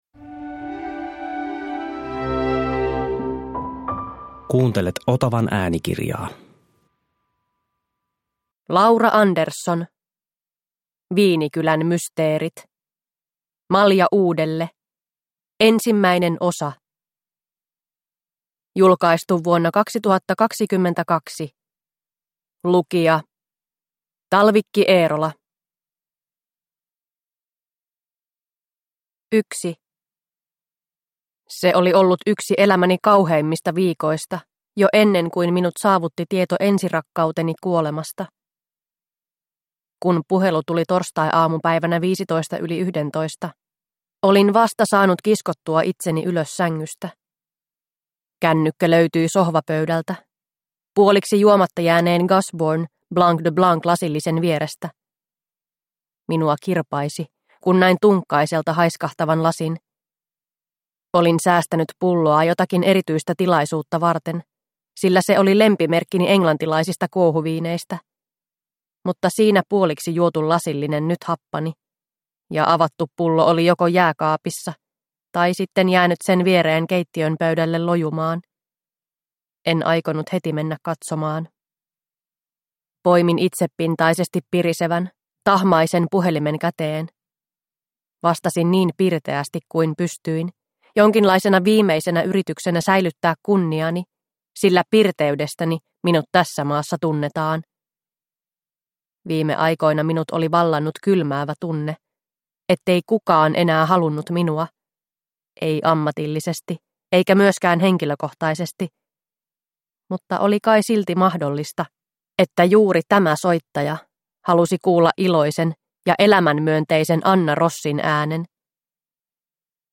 Malja uudelle 1 – Ljudbok – Laddas ner